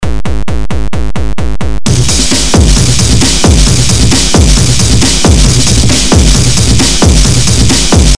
Some random old bits, raw and bloody.
I need to experiment more with noizy filters over droning synths. Gives a nice creepy effect.